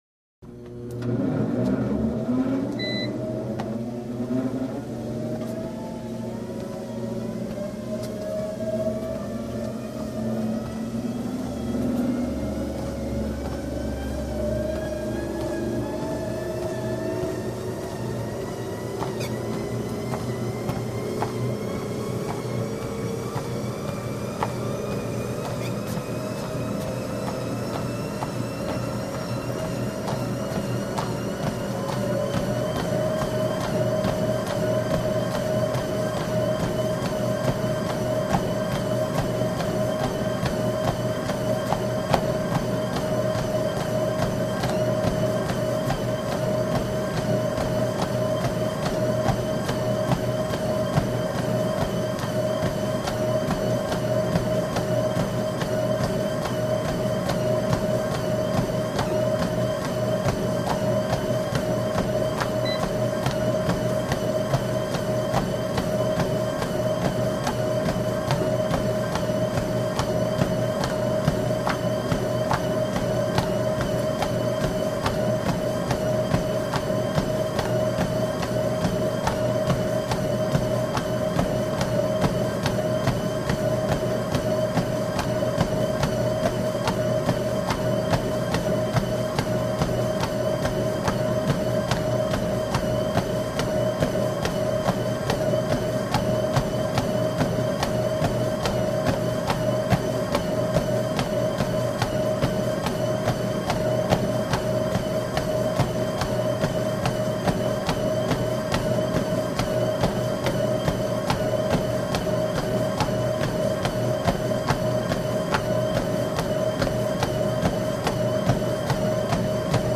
TreadmillStartJog WES030201
Cardiovascular Exercise; Treadmill Starts Up Slowly Increasing In Speed To A Jog. One Jogger.